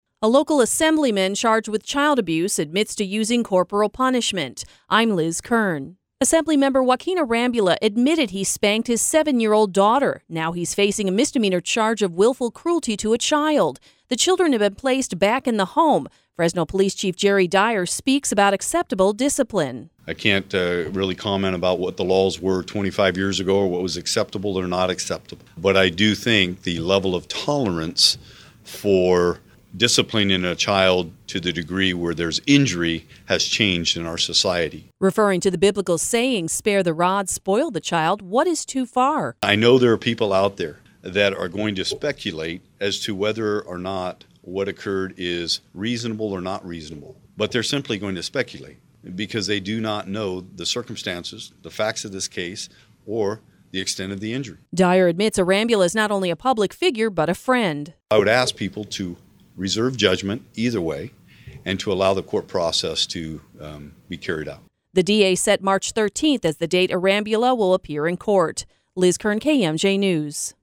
Fresno Police Chief Jerry Dyer speaks about acceptable discipline
Dyer spoke during his monthly Crime View report on Wednesday, answering media questions about the arrest.